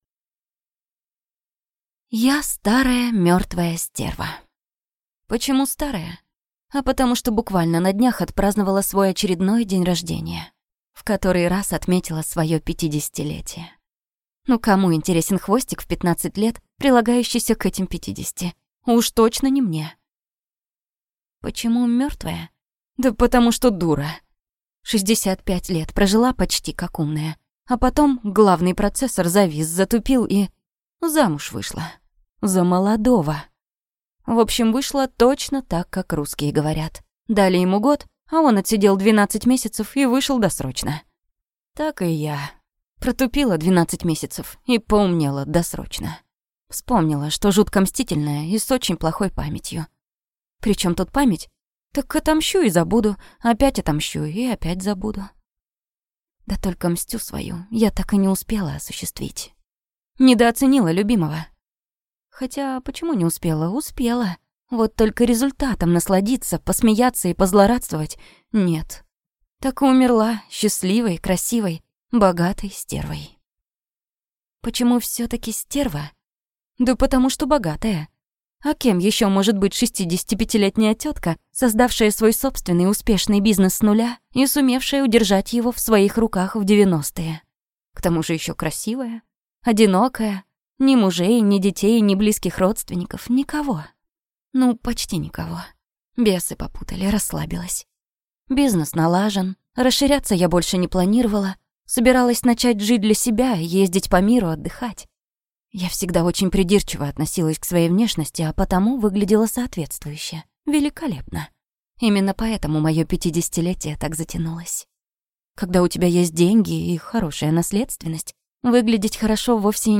Аудиокнига Я не ангел | Библиотека аудиокниг